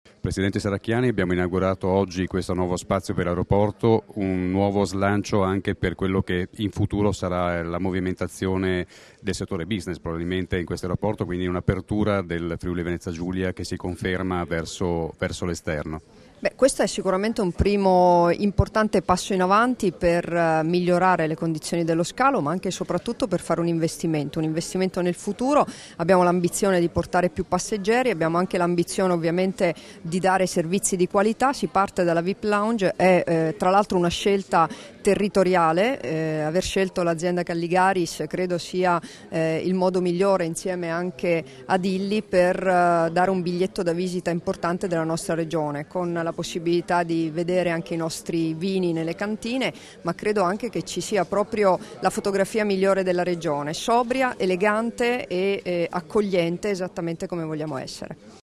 Dichiarazioni di Debora Serracchiani (Formato MP3) [935KB]
a margine dell'inaugurazione della Sala Calligaris VIP Lounge all'Aeroporto FVG, rilasciate a Ronchi dei Legionari il 6 aprile 2016